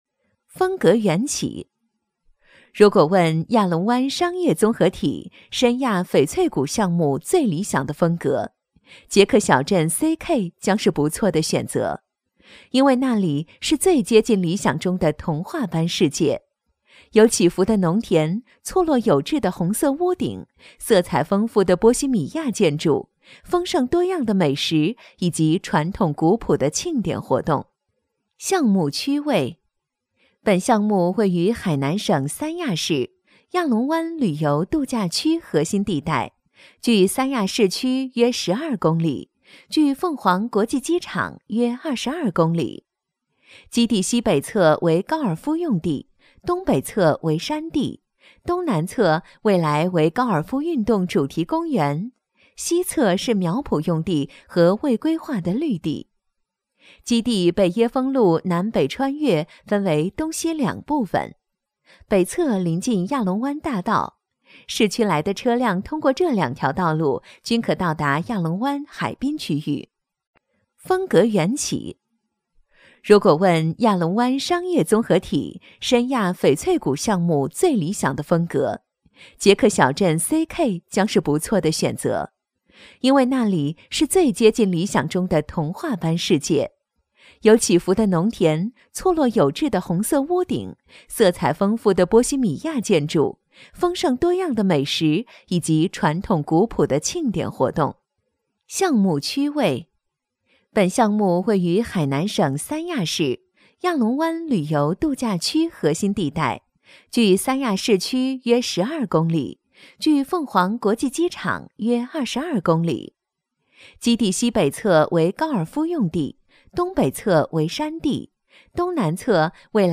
• 女S12 国语 女声 宣传片-地产项目介绍-年轻时尚 沉稳|积极向上|亲切甜美